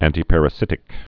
(ăntē-părə-sĭtĭk, ăntī-)